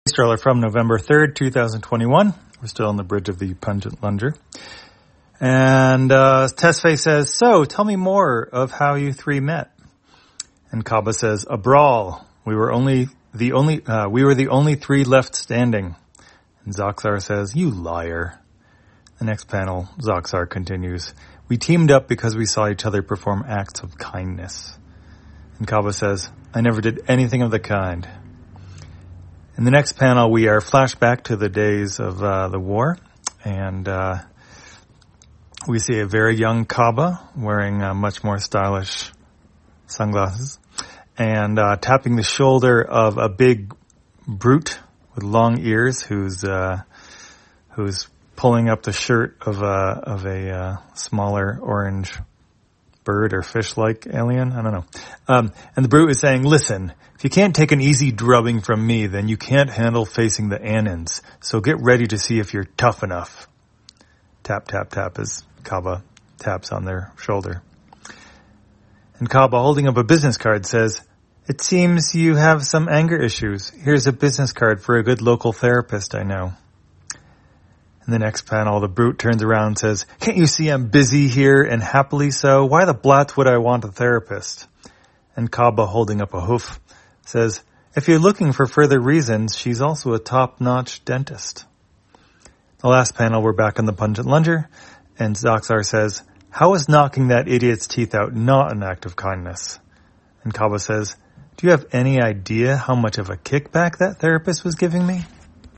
Spacetrawler, audio version For the blind or visually impaired, November 3, 2021.